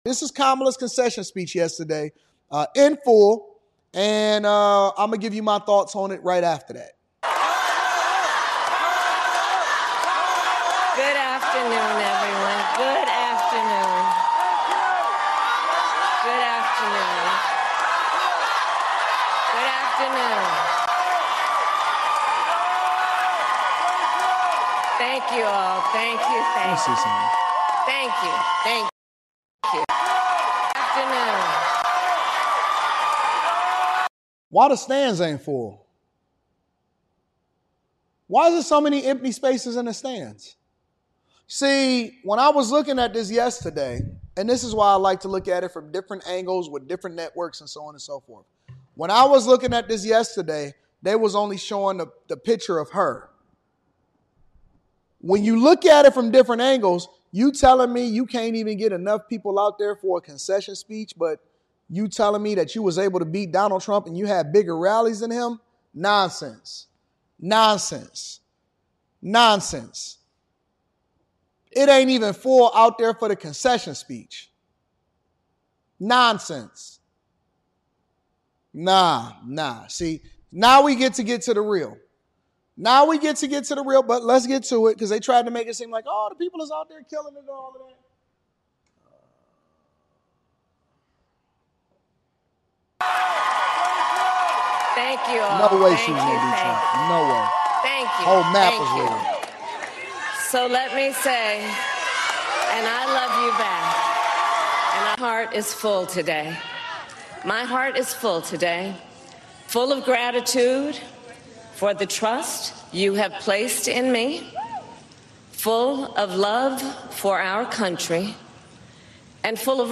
➡ The speaker discusses a political figure’s speech, critiquing her delivery and content.